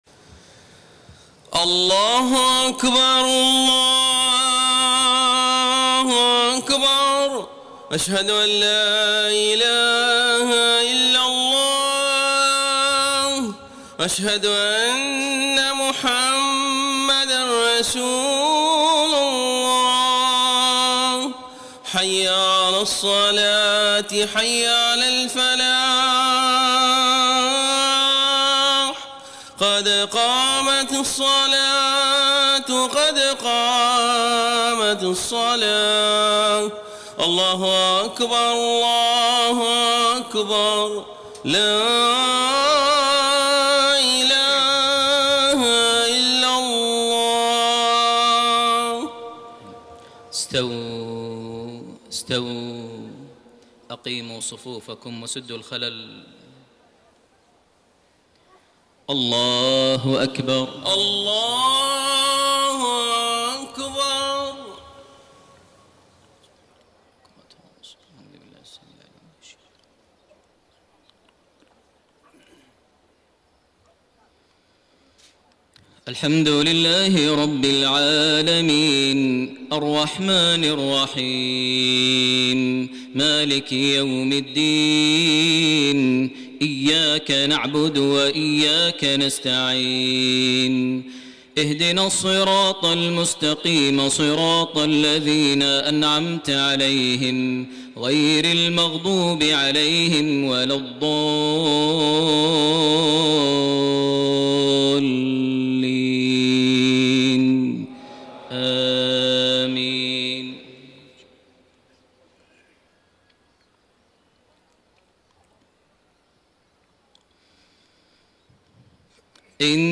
صلاة المغرب 25 جمادى الآخرة 1433هـ من سورة النجم 27-47 > 1433 هـ > الفروض - تلاوات ماهر المعيقلي